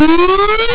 ezweb用コンテンツのために44.1KHzのWAVEファイルを8000Hzにした時の音質を比較してみました。
WAVEファイルアイコン ディフォルトのクオリティーでやってみました。このソースではそこまで差が出ませんが多少違うという事はわかりました。
synth_origin_rs20.wav